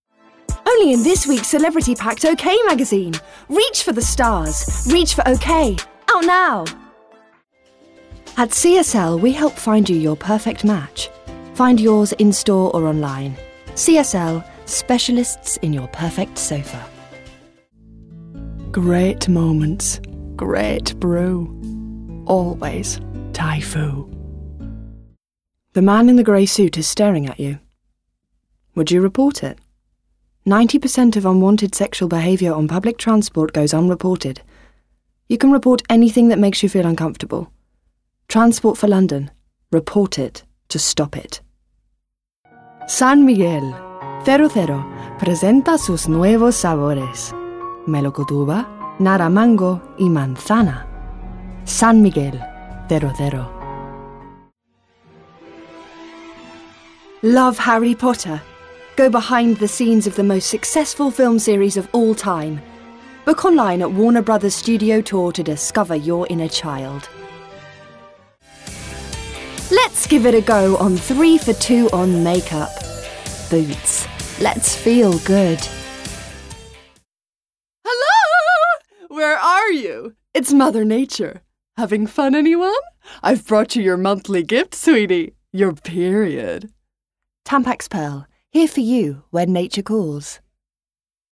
English neutral, Warm, Smooth, Versatile, Engaging